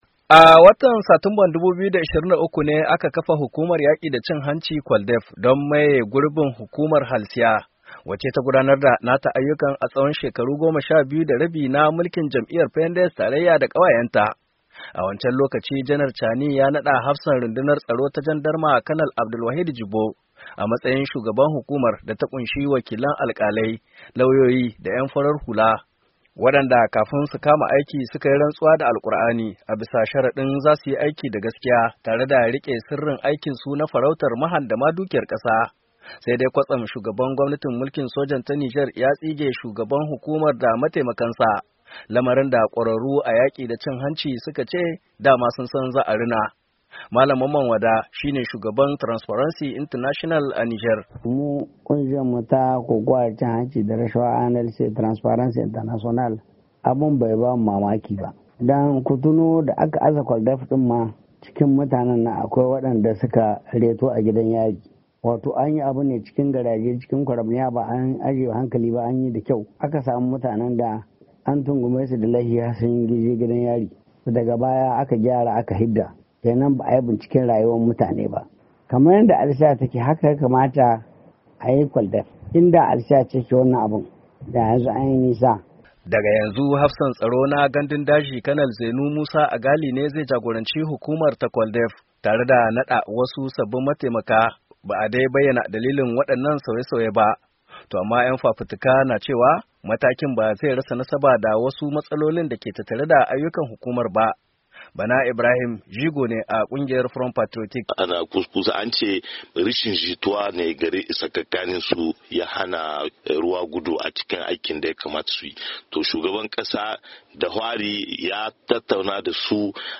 NIAMEY, NIGER —